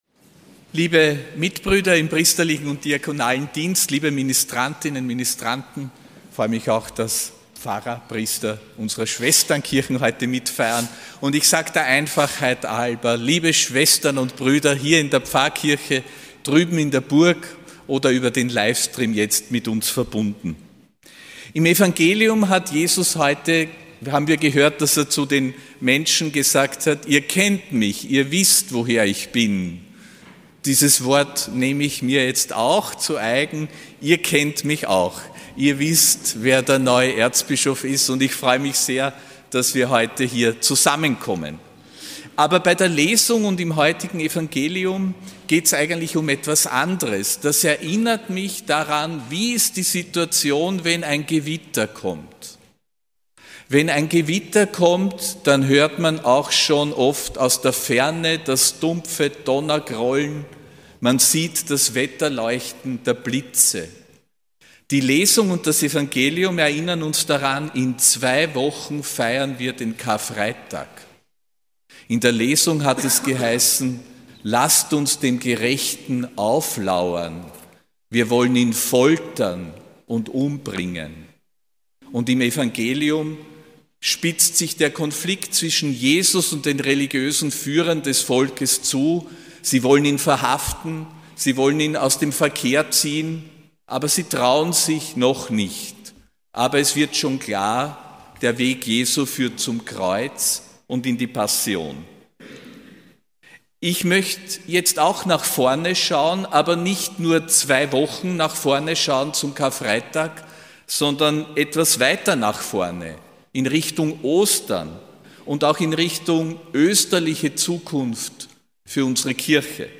Predigt von Erzbischof Josef Grünwidl bei der Bischofsmesse in Perchtoldsdorf, am 20. März 2026.